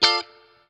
CHORD 2   AF.wav